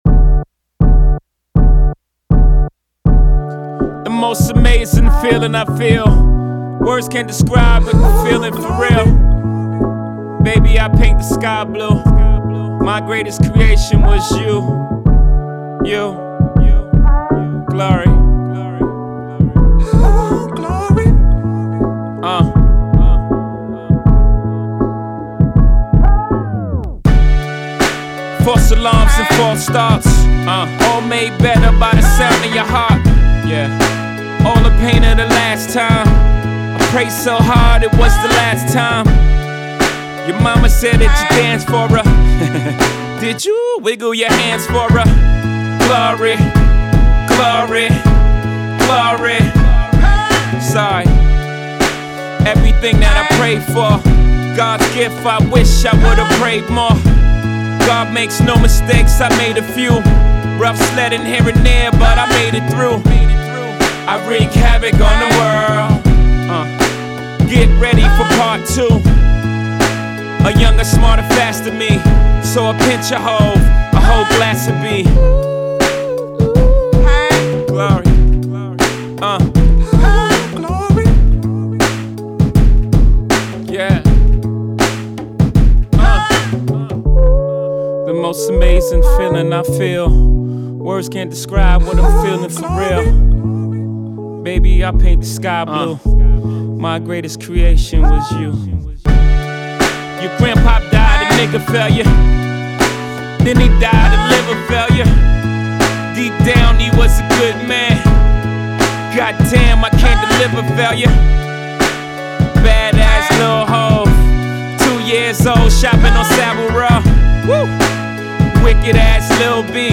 introspective mood